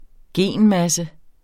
Udtale [ ˈgeˀn- ]